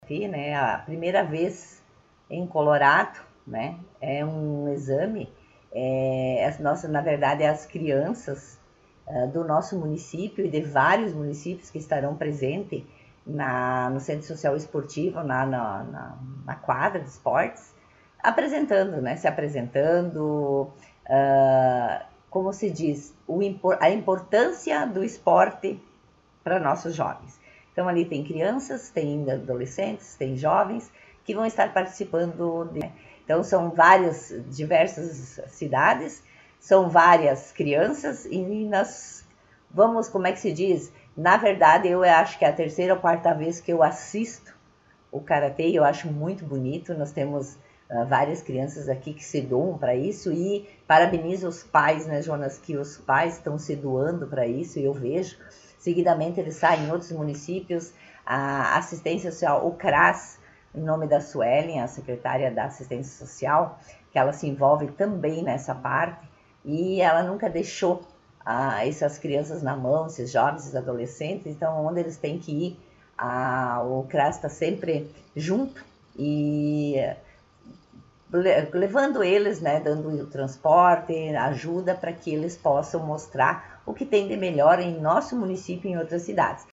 Vice-prefeita Marta Mino concedeu entrevista